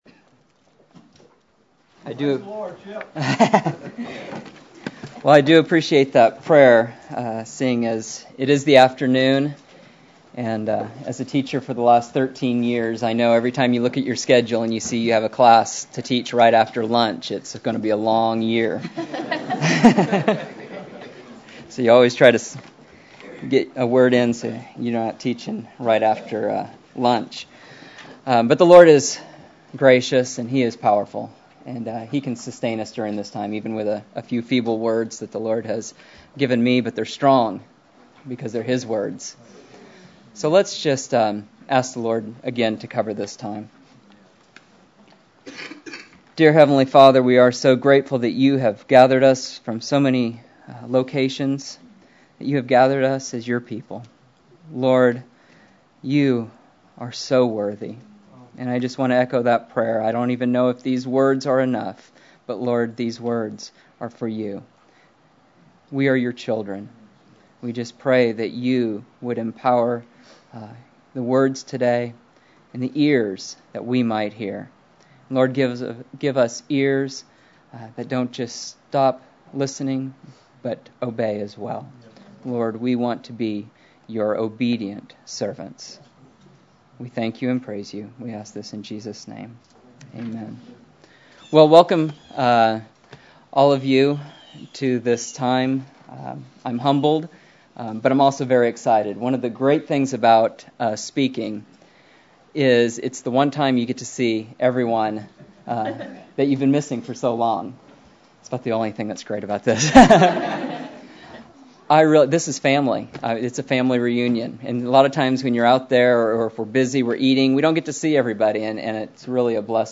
A collection of Christ focused messages published by the Christian Testimony Ministry in Richmond, VA.
2009 Memphis Conference: A Call To Build